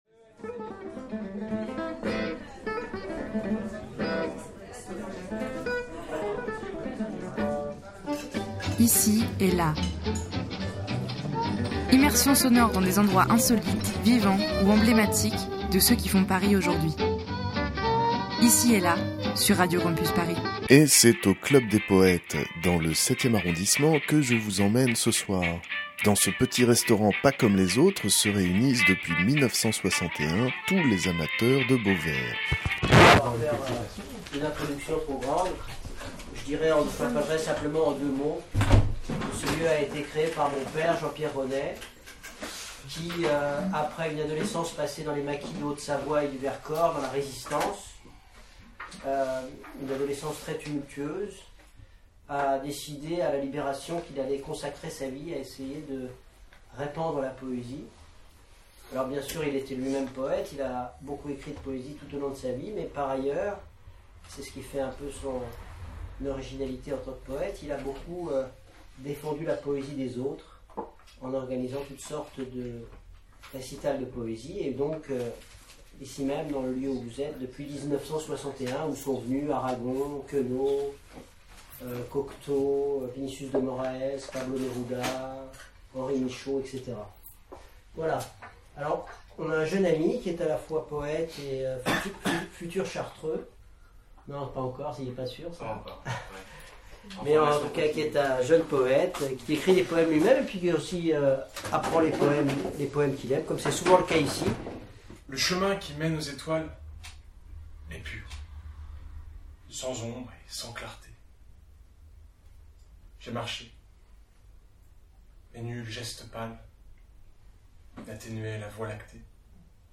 Troisième virée parisienne avec Ici et là. Aujourd'hui on vous emmène au Club des Poètes, un petit cabaret du 7ème arrondissement ...